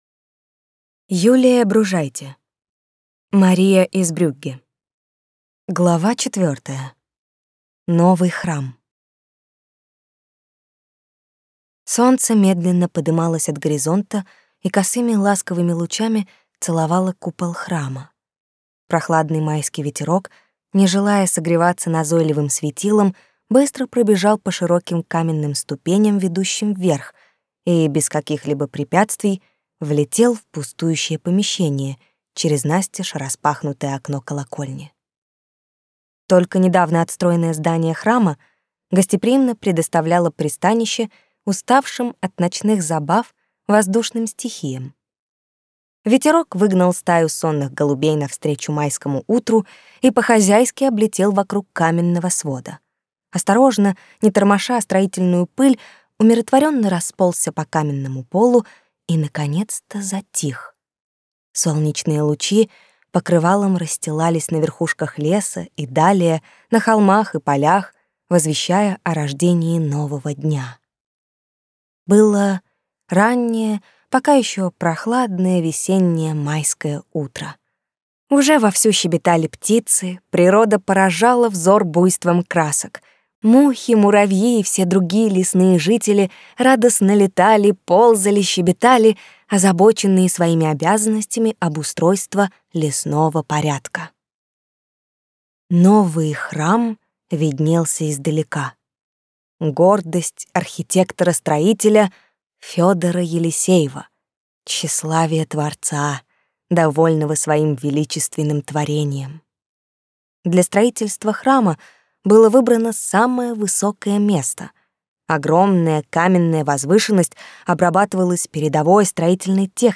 Аудиокнига Мария из Брюгге | Библиотека аудиокниг